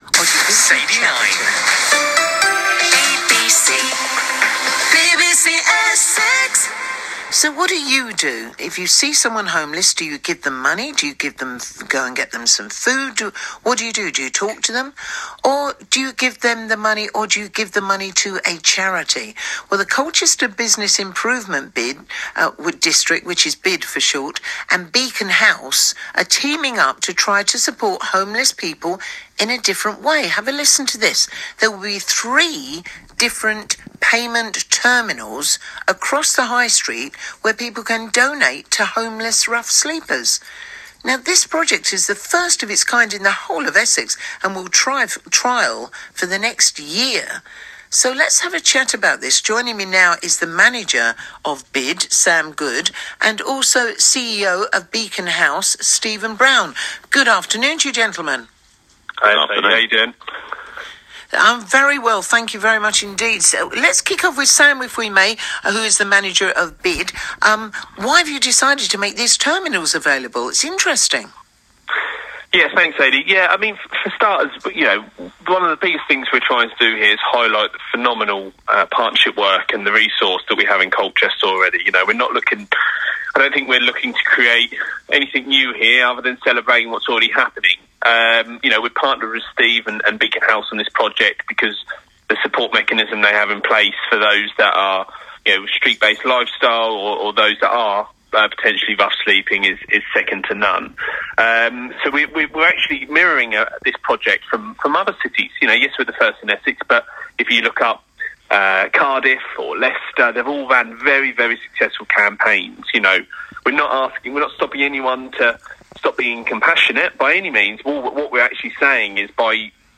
BBC Essex Giving Interview